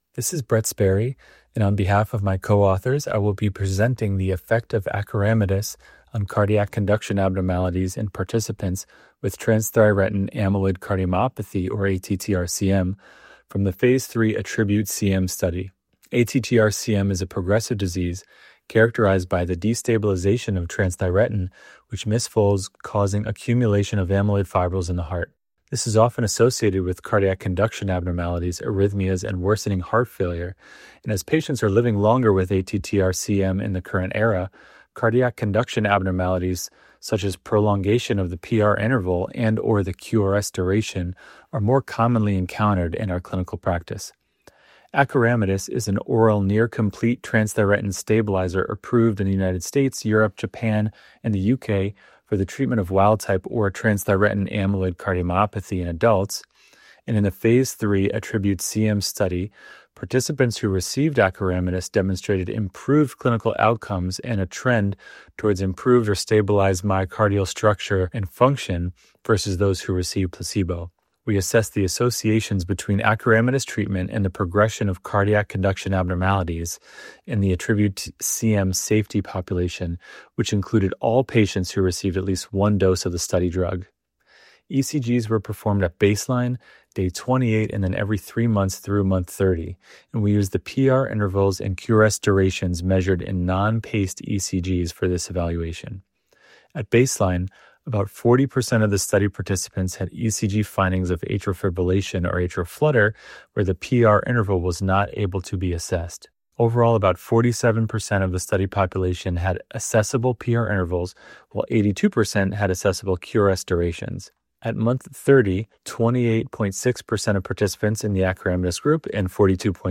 Listen to a short talk from the speaker